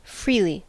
Ääntäminen
Vaihtoehtoiset kirjoitusmuodot (Skotlannin englanti) frely Synonyymit costlessly Ääntäminen US US : IPA : /ˈfɹili/ Haettu sana löytyi näillä lähdekielillä: englanti Määritelmät Adjektiivit Free ; frank .